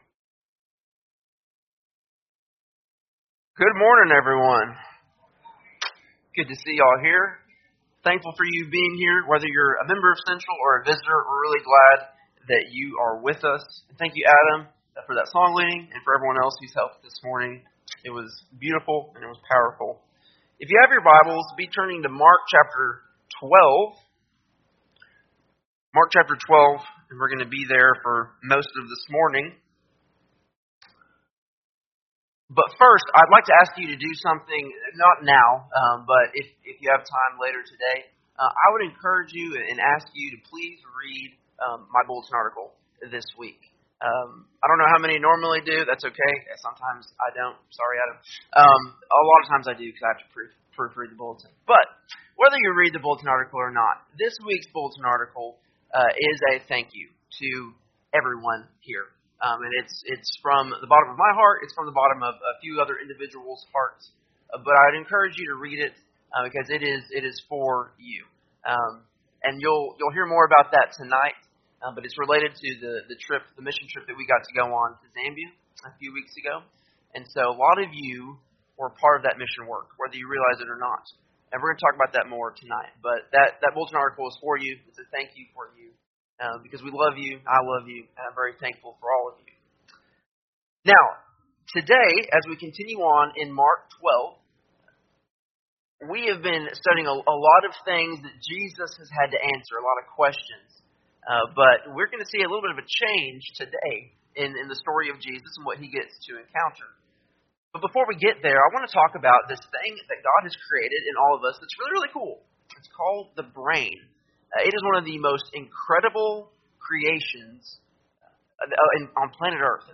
Sunday AM Sermon
7-21-24-Sunday-AM-Sermon.mp3